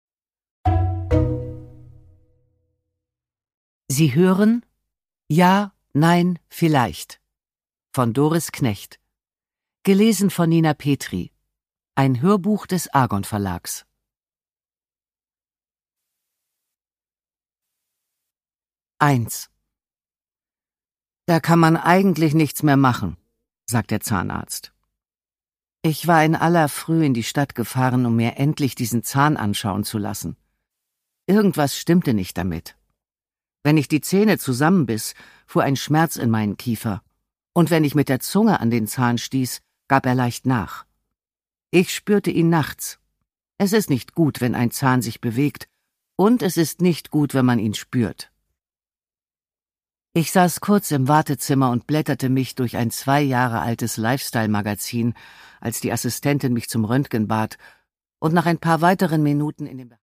Produkttyp: Hörbuch-Download
Gelesen von: Nina Petri